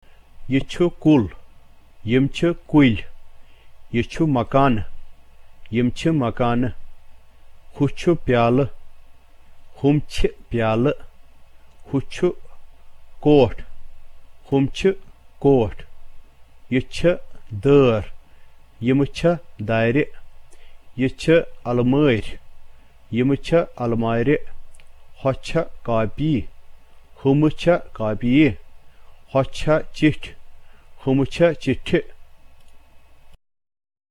II. Transformation Drill